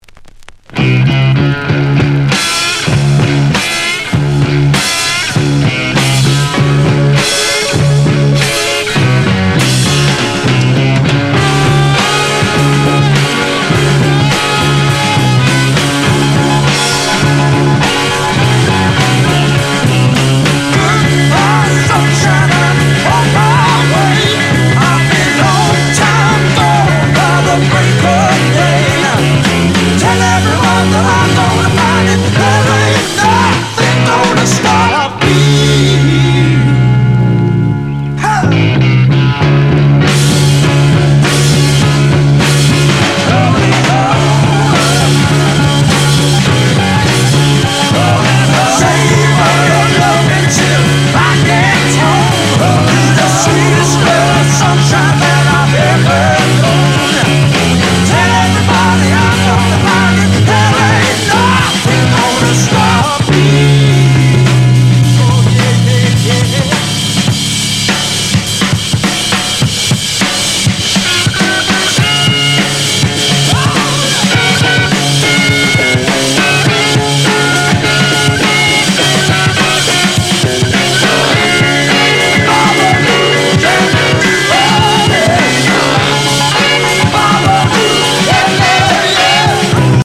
• 特記事項: MONO
▲DISC: 小キズ散見。ところどころノイズ有（➡要試聴）